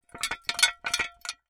Metal_54.wav